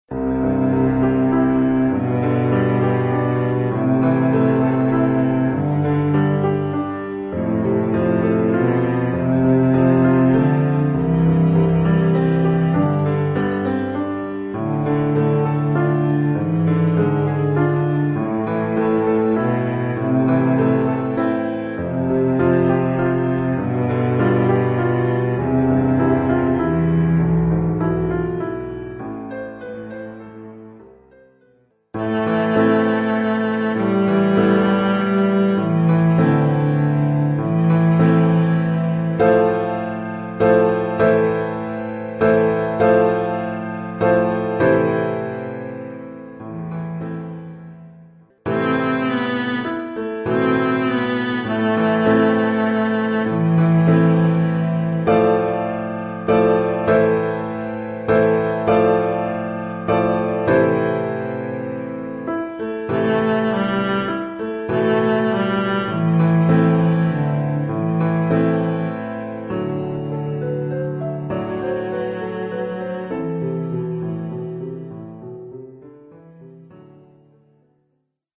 Oeuvre pour violoncelle et piano.
Niveau : débutant (1er cycle).